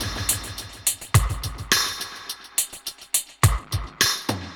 Index of /musicradar/dub-drums-samples/105bpm
Db_DrumKitC_EchoKit_105-02.wav